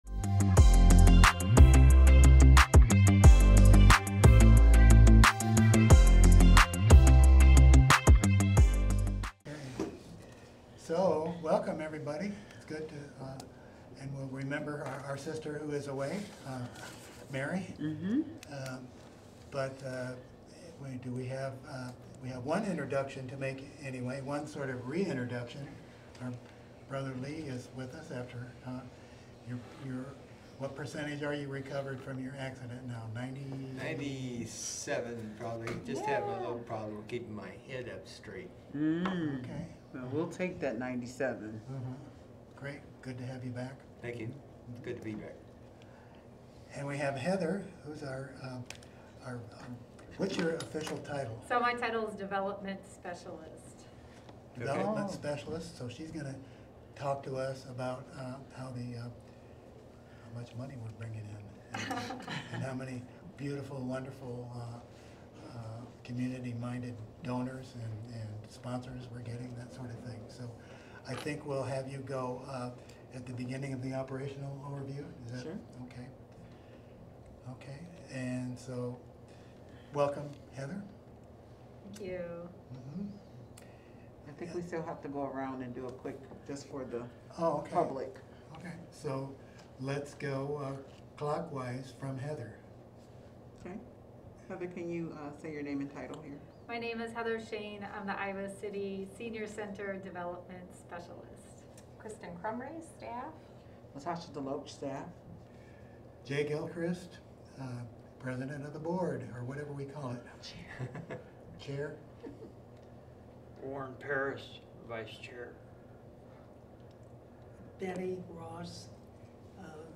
Regular monthly meeting of the Senior Center Commission.